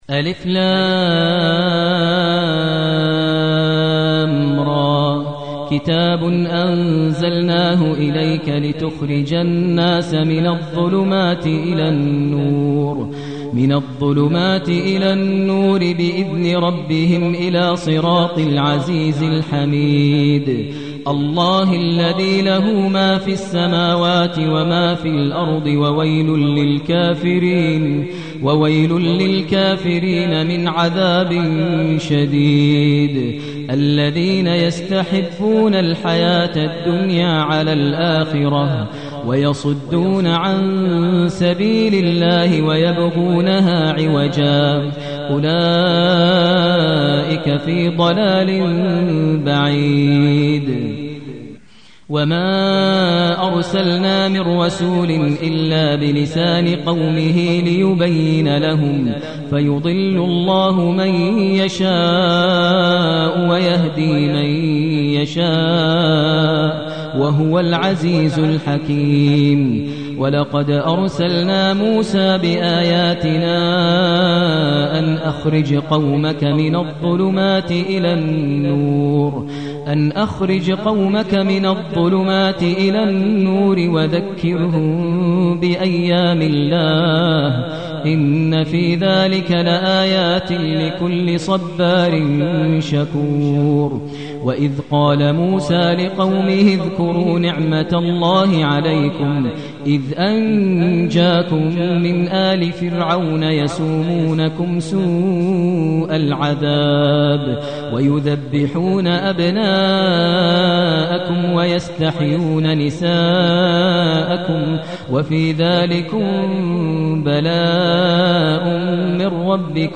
المكان: المسجد النبوي الشيخ: فضيلة الشيخ ماهر المعيقلي فضيلة الشيخ ماهر المعيقلي إبراهيم